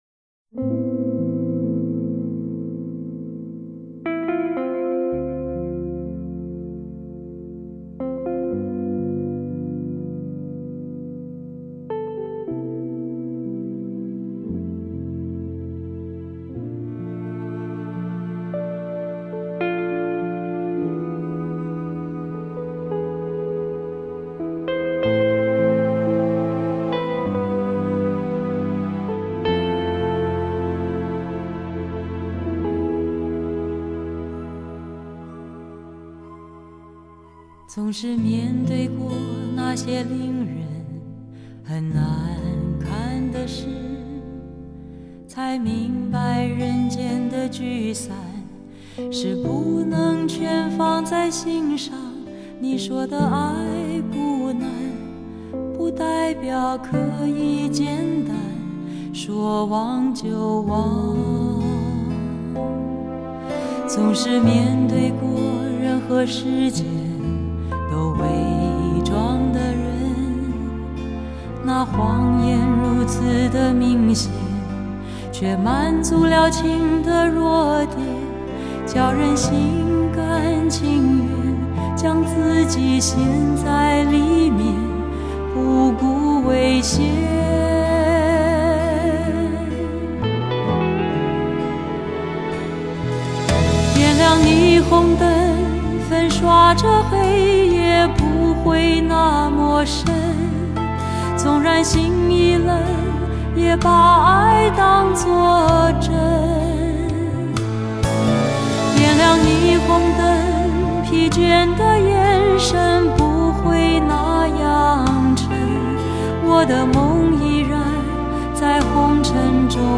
她，温厚的声音，打动了多少人的心，抚慰了多少人的心灵……